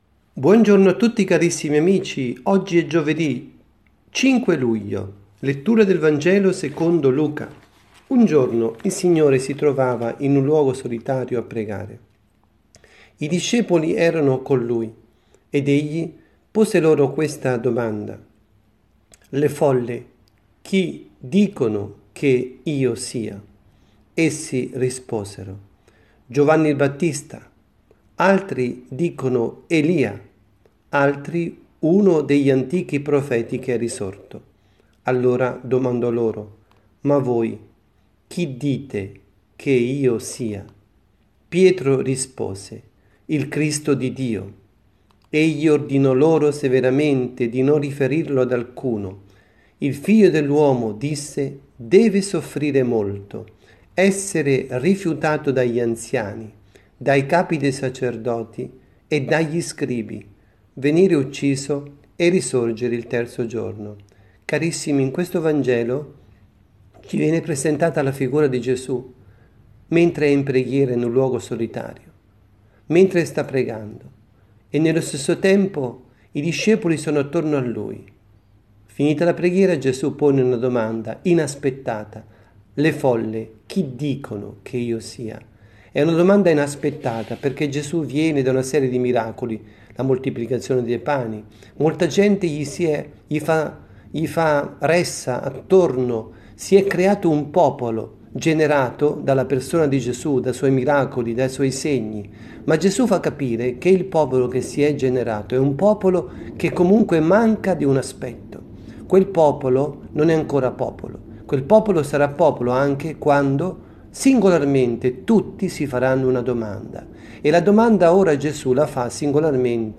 avvisi, Omelie
Catechesi
dalla Parrocchia S. Rita – Milano